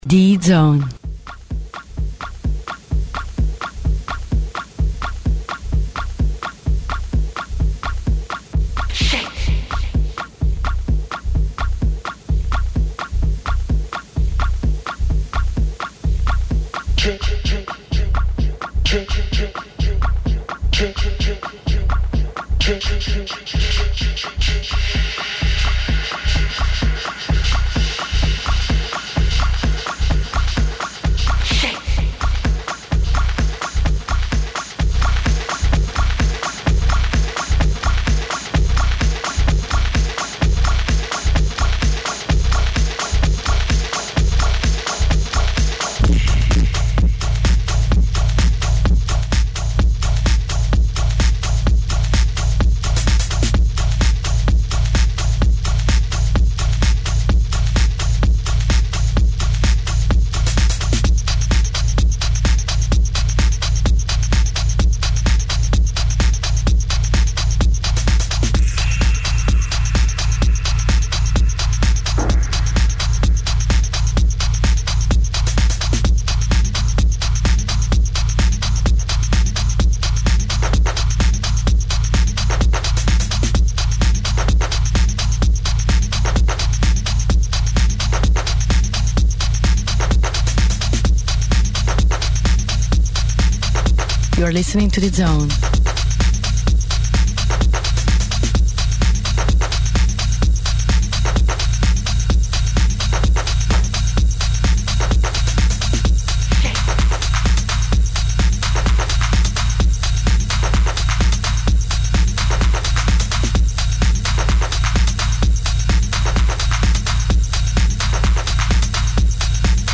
LIVE ONAIR
Techno
back2back